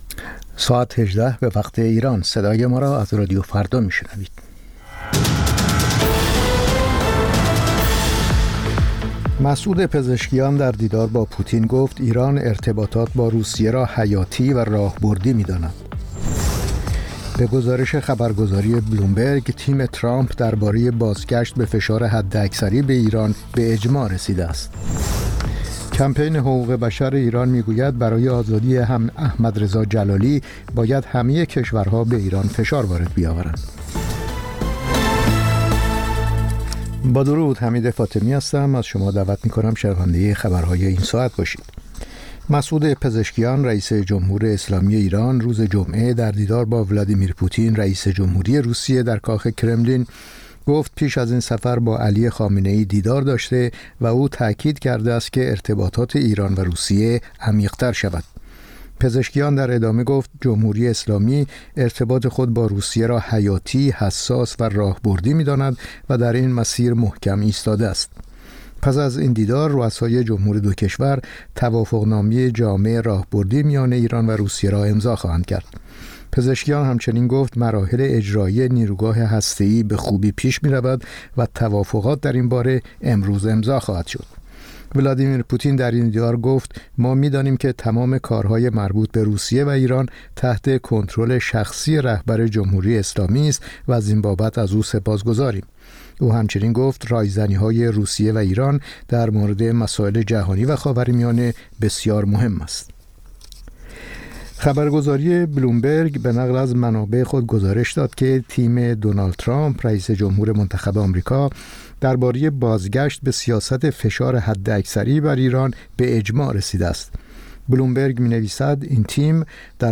سرخط خبرها ۱۸:۰۰